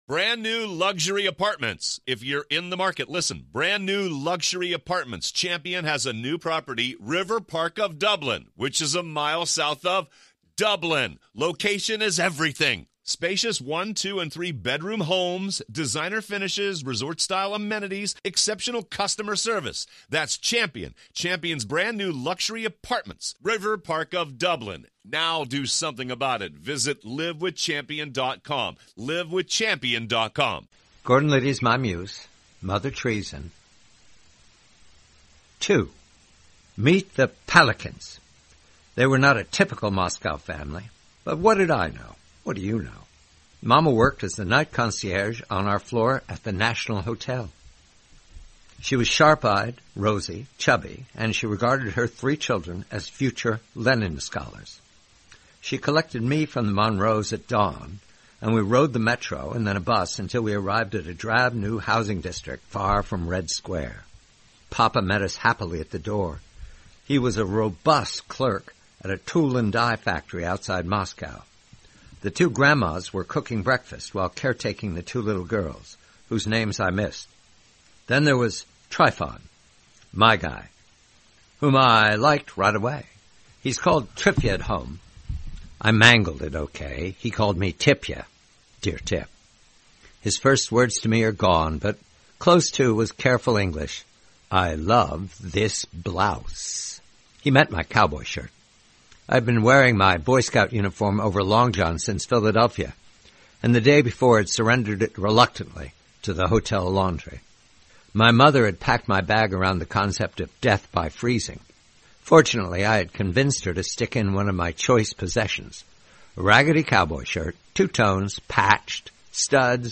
Read by John Batchelor.